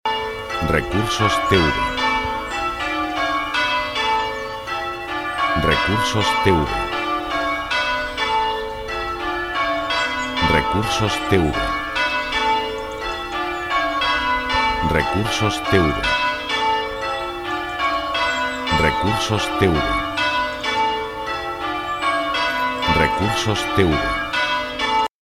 Sonido de campanas sonando en Catedral o Iglesia
campanas_de_catedral_sonando.mp3